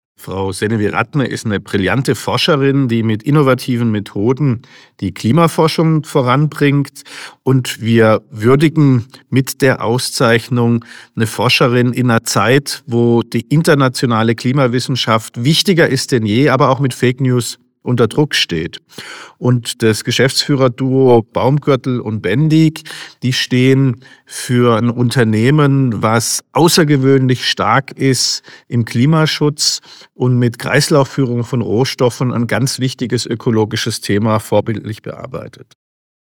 Frage 1 an Alexander Bonde